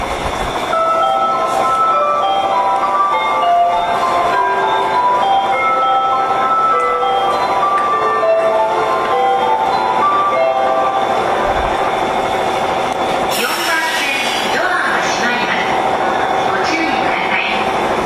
常磐線(各駅停車) 曲名不明 柏駅1番線と同じだそうです